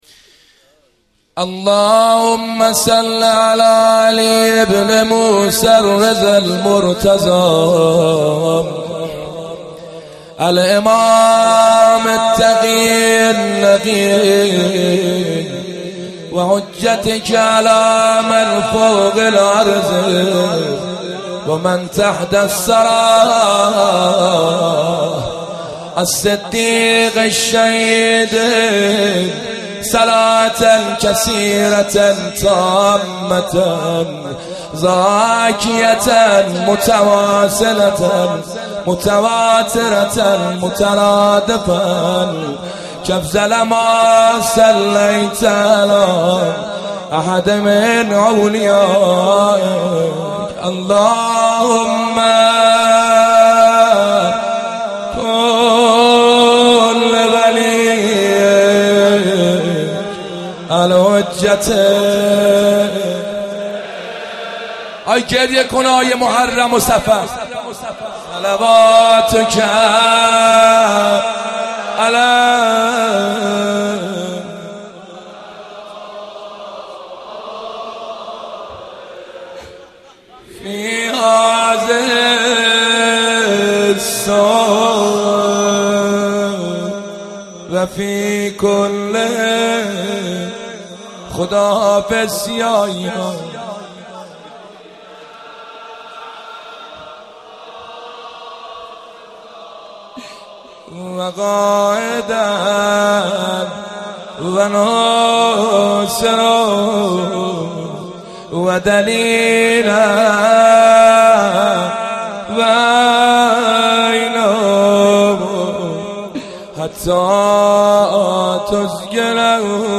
گلچین بهترین مداحی
به مناسبت شهادت امام رضا علیه السلام
روضه شهادت امام رضا